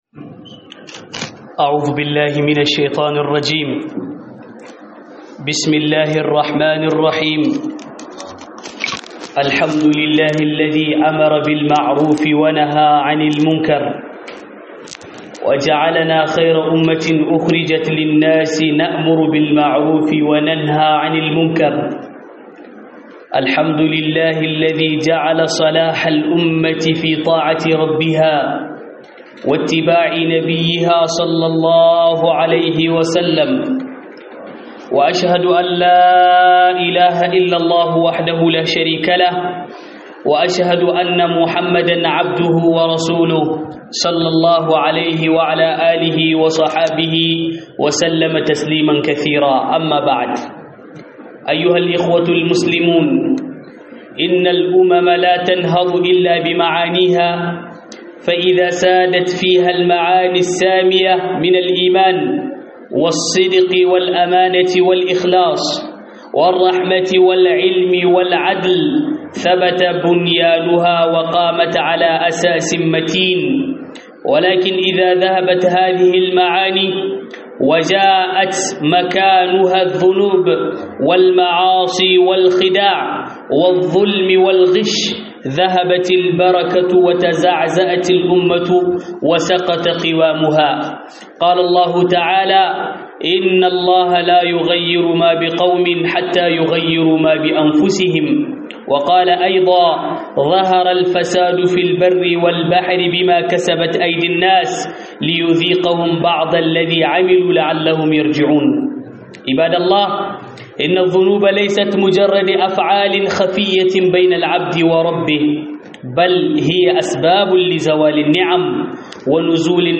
Huɗubar juma'a Tasirin Zunubi